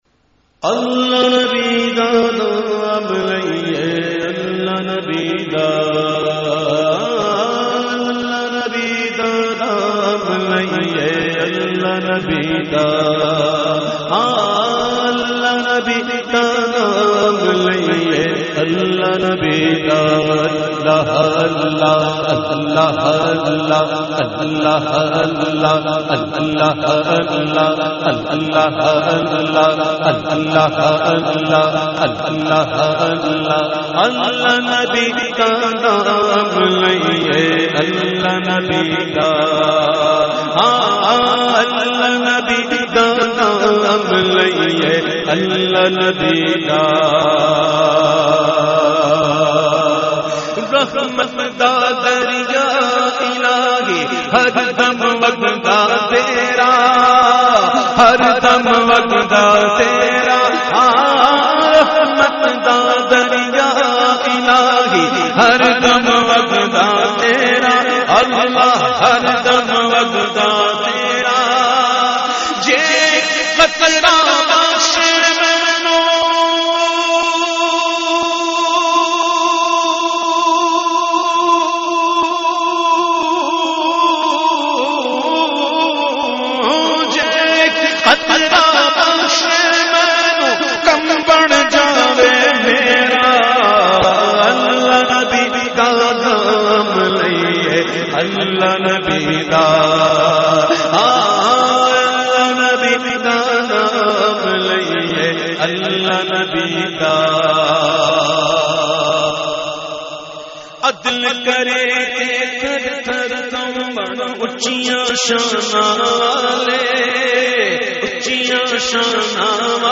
Category : Hamd | Language : Urdu